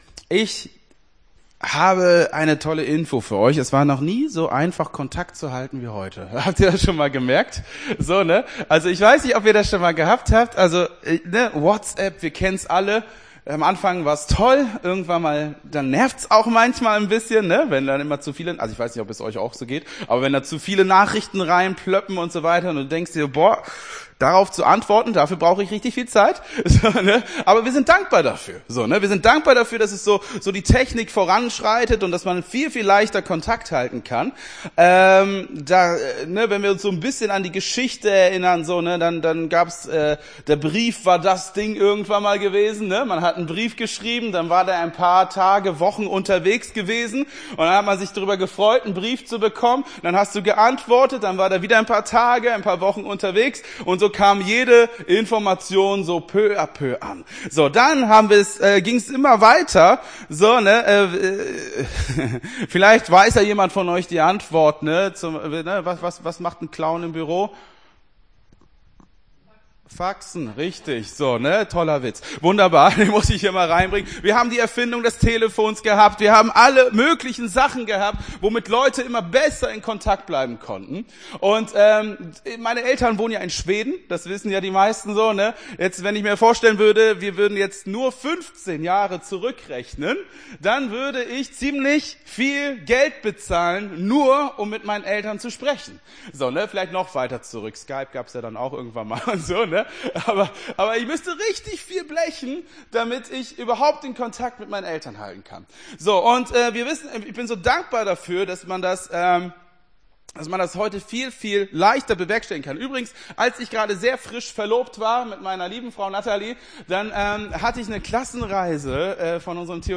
Gottesdienst 07.07.24 - FCG Hagen